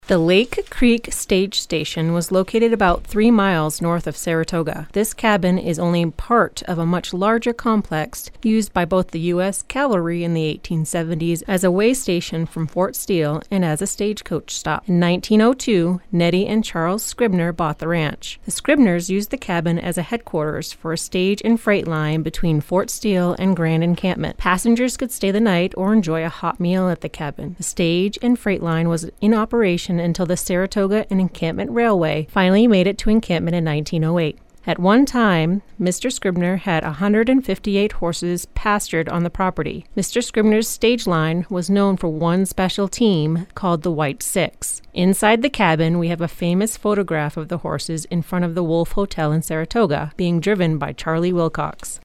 Audio Tour: